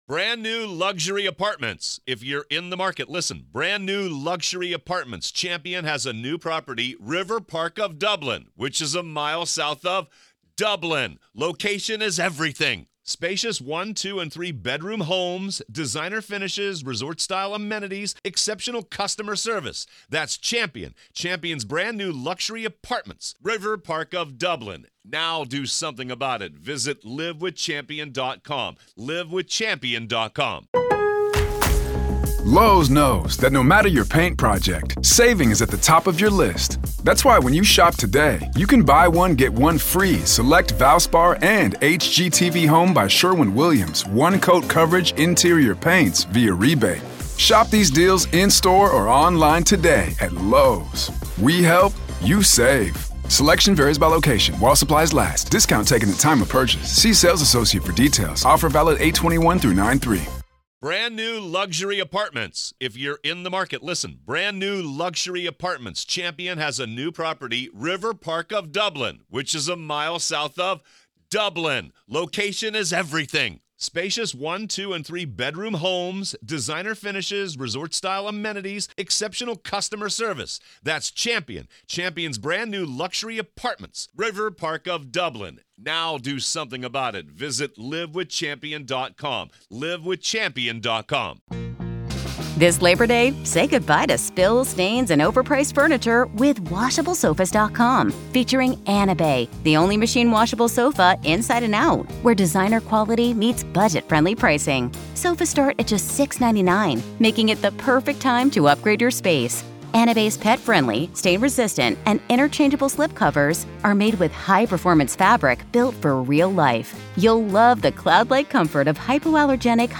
And through it all, you’ll hear Lori herself—calm, controlled, representing herself, cross-examining her own family, and showing little to no emotion as her beliefs, her behavior, and her potential motives are laid bare. This is the complete courtroom audio from April 10, 2025 —no edits, no filters, just the raw reality of one of the most bizarre and disturbing murder trials in modern history.